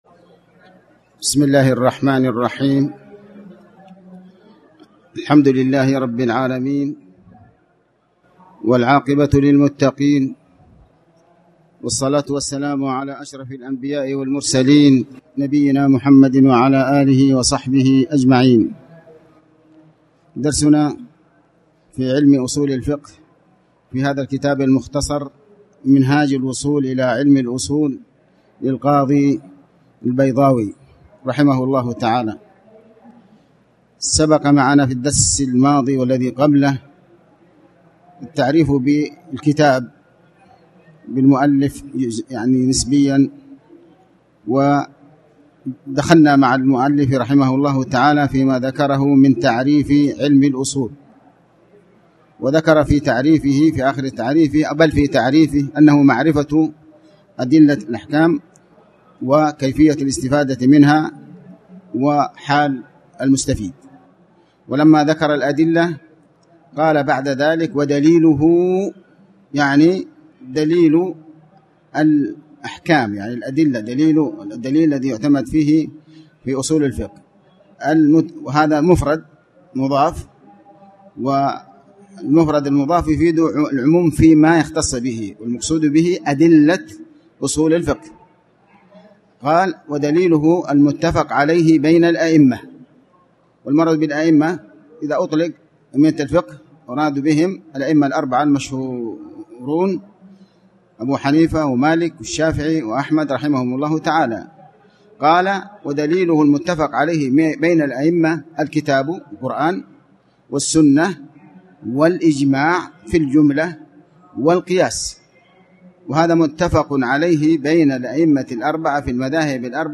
تاريخ النشر ٢٨ محرم ١٤٣٩ هـ المكان: المسجد الحرام الشيخ